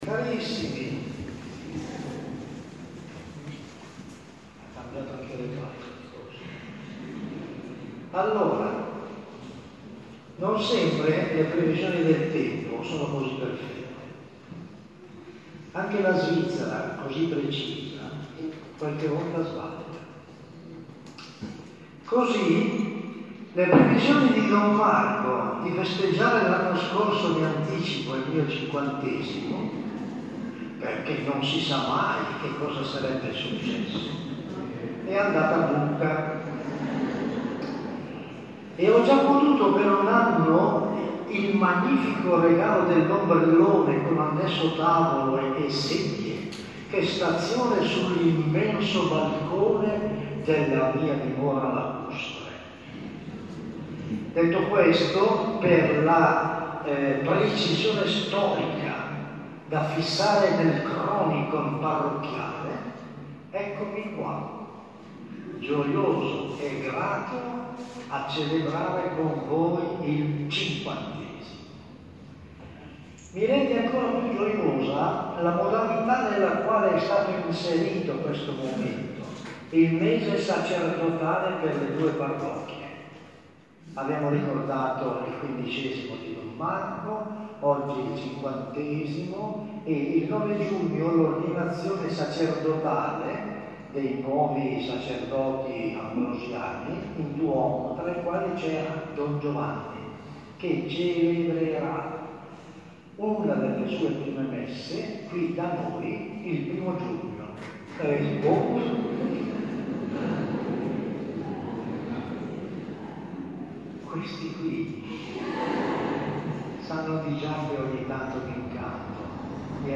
Categoria: Omelie